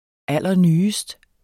Udtale [ ˈalˀʌˈnyːəsd ]